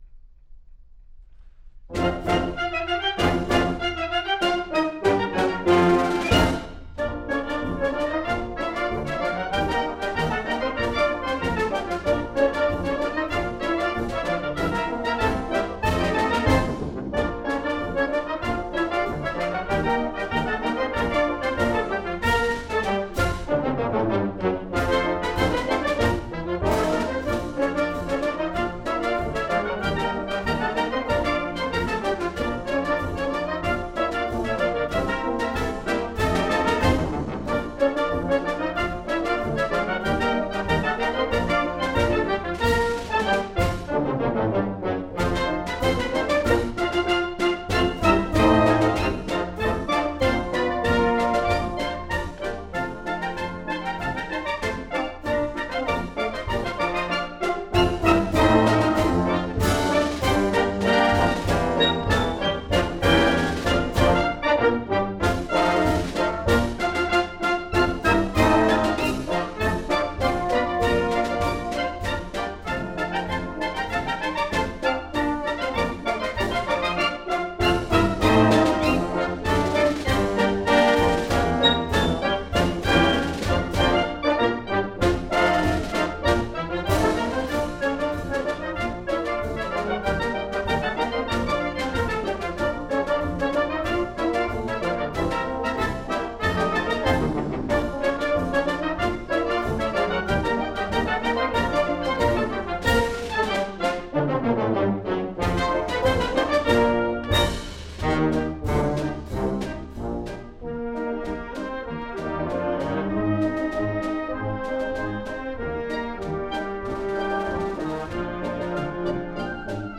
using a pair of Oktava MK-12 omnidirectional
microphones, with an Edirol UA-25 A/D resulting in 24 bit 96KHz master